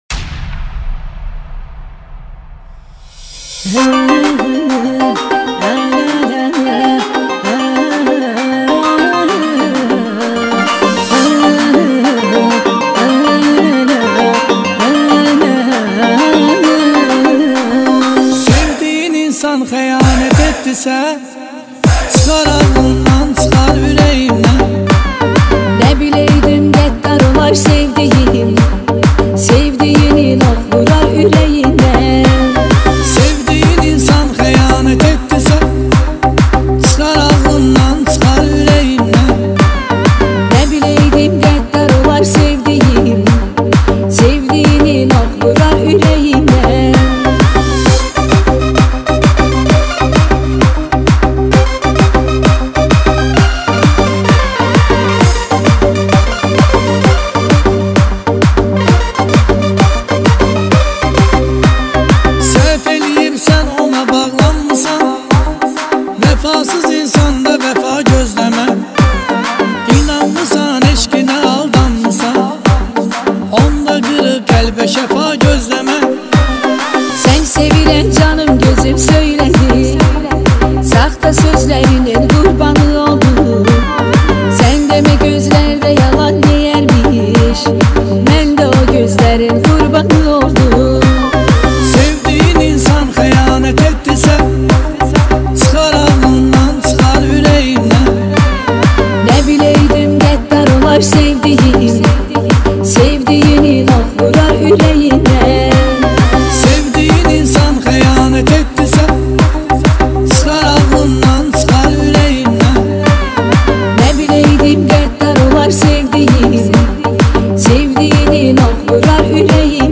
это эмоциональная композиция в жанре поп-фолк